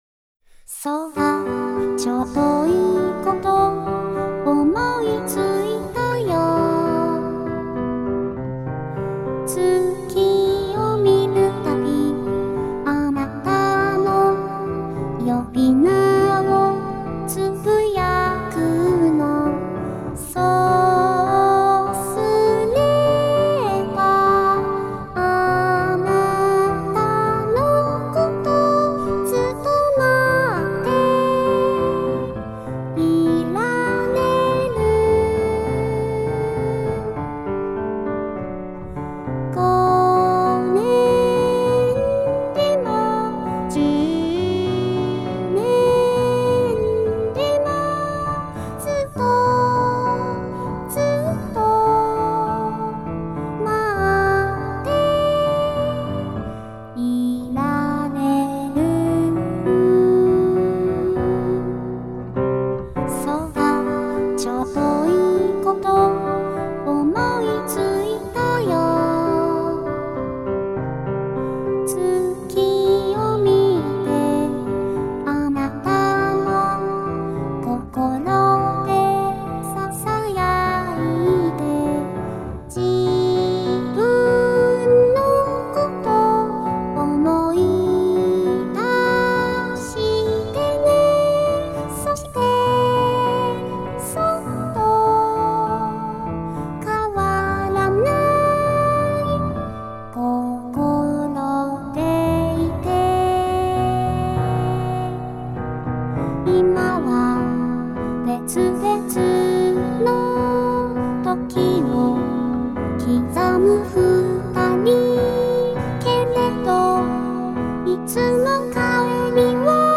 お月さま･･･(初音ミクver.)
moon6-miku.mp3